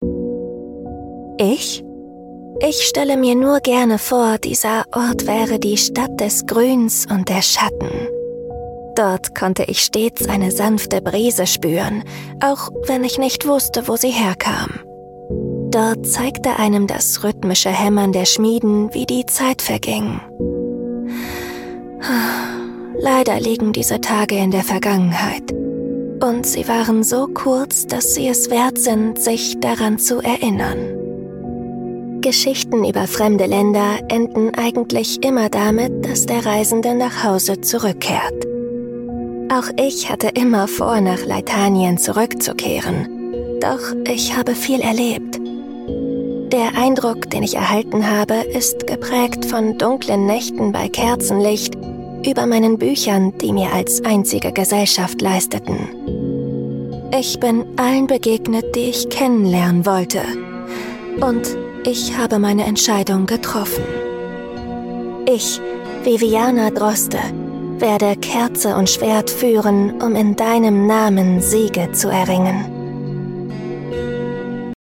Werbung für Kinder - Magix Schulranzen